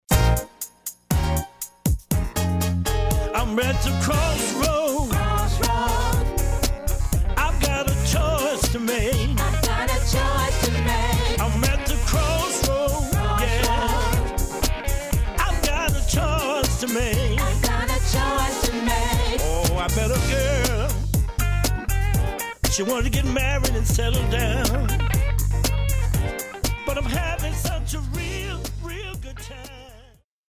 a lot of funk and Motown soul mixed in